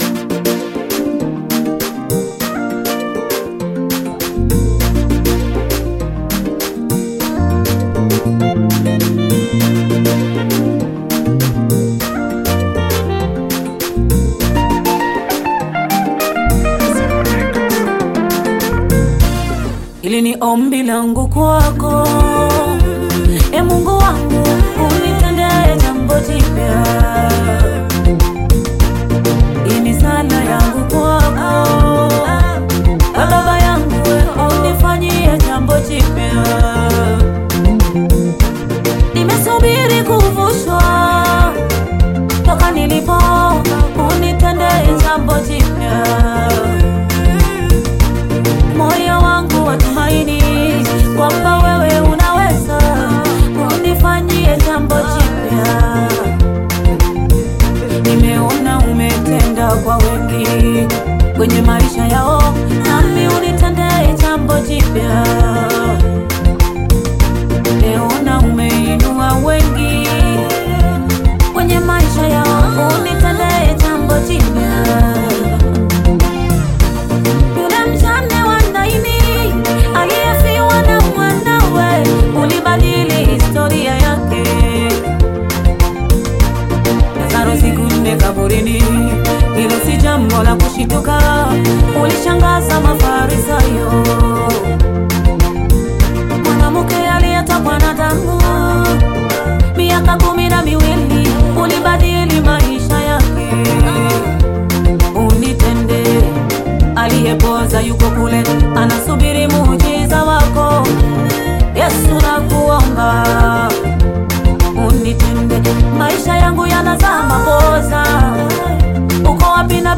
gospel track
Gospel singer